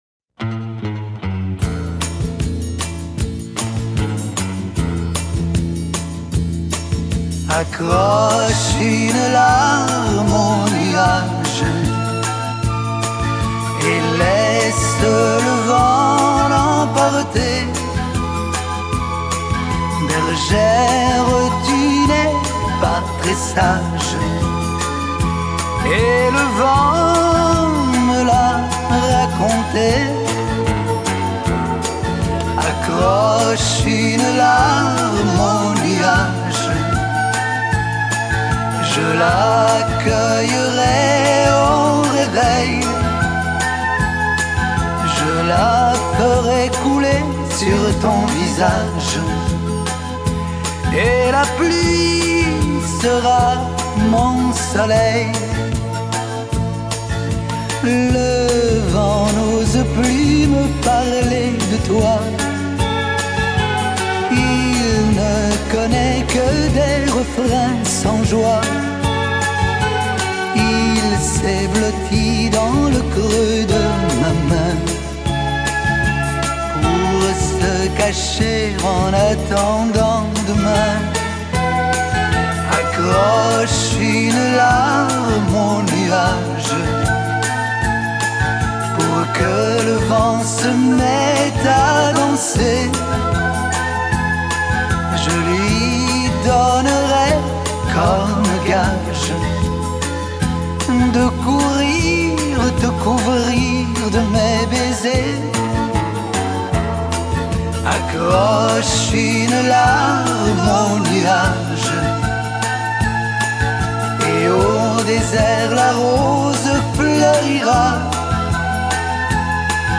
Красиво но к сожалению не то и банджо не прослушиватся.